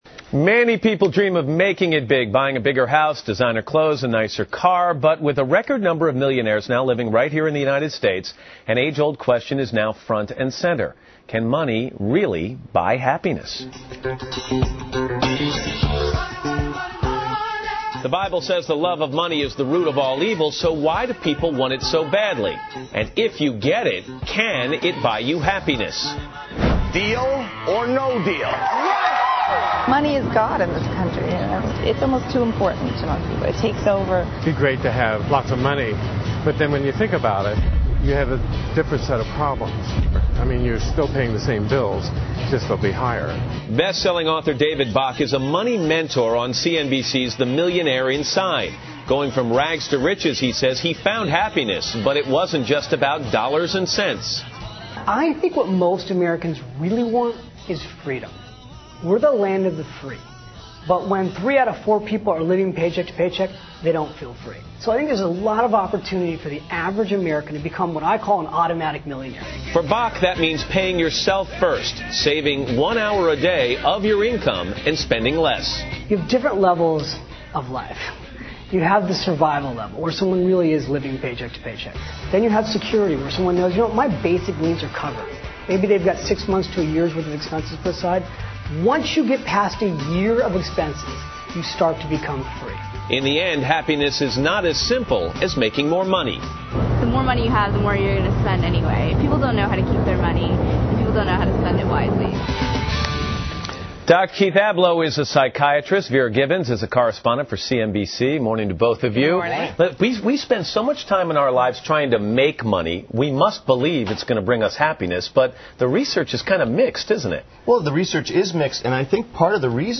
访谈录 Interview 2007-07-20&07-22, 用金钱买幸福？